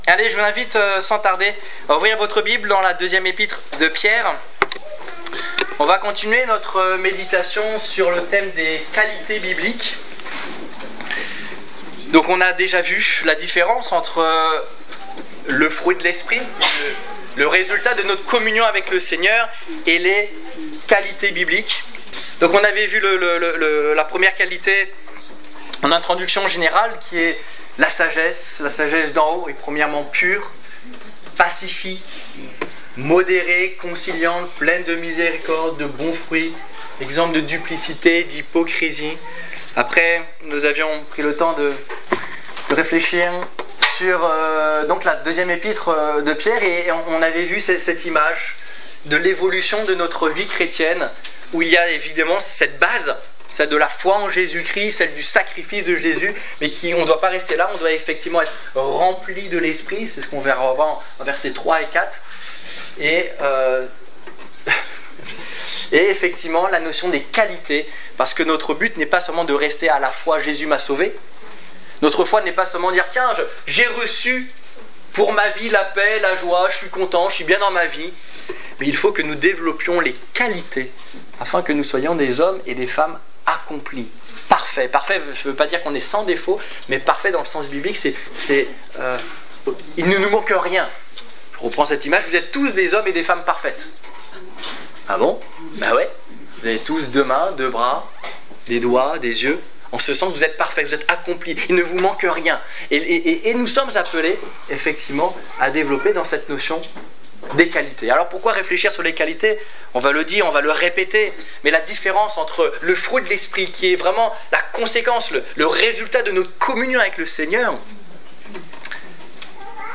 Quelques qualités bibliques - La connaissance Détails Prédications - liste complète Culte du 27 septembre 2015 Ecoutez l'enregistrement de ce message à l'aide du lecteur